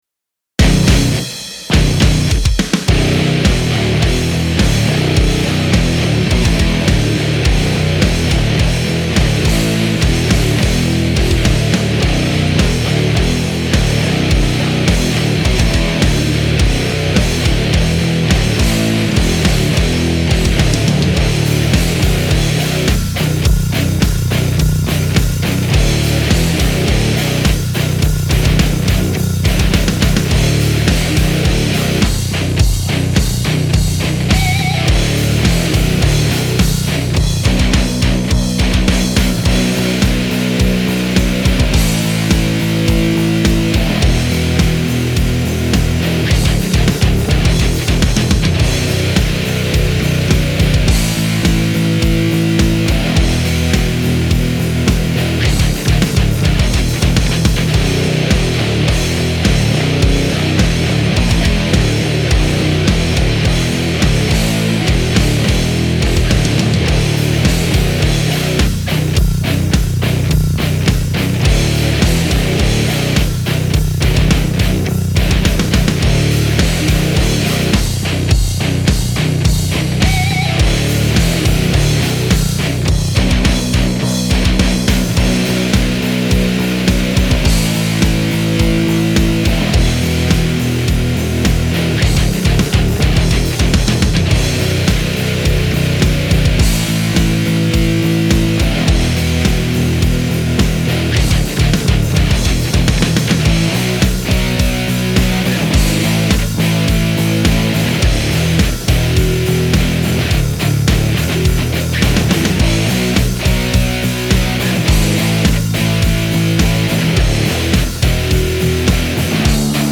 hauptsache es rockt..